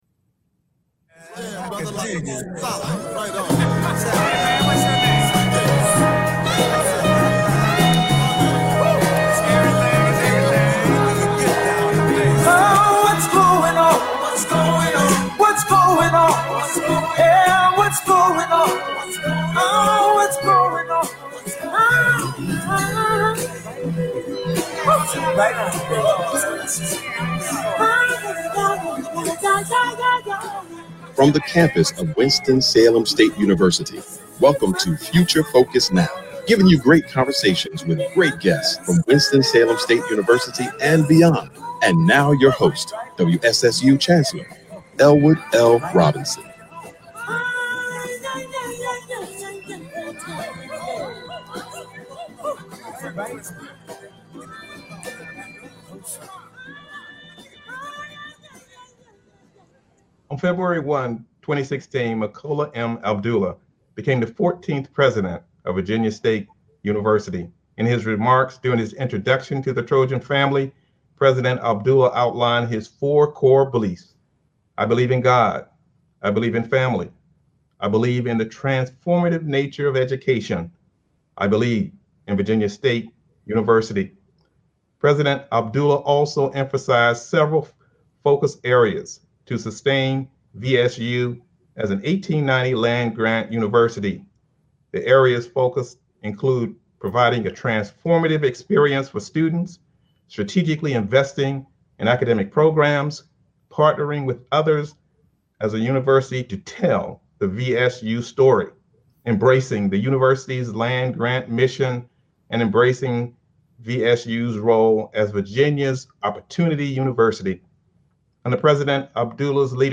Future Focus is a one-hour public affairs talk show